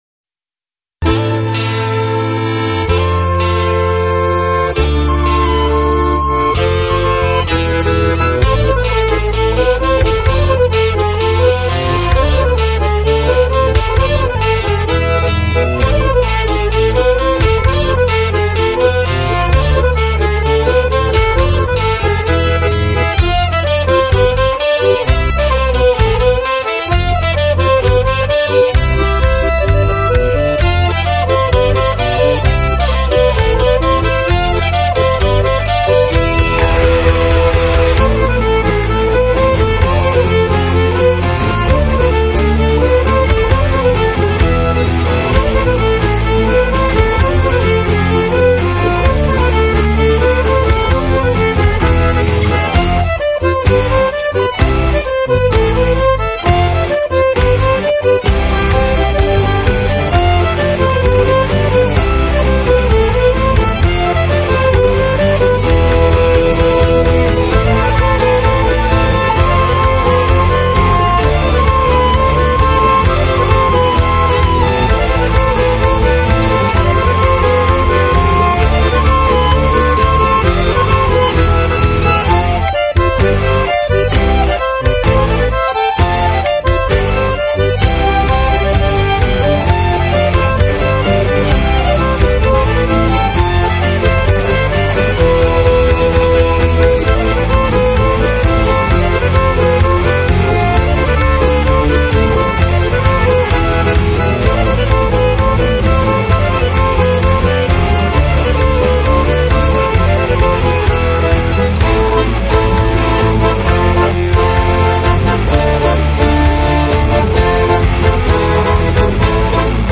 Bourrée 2 temps “L’Elan” (Bal de l’éphémère)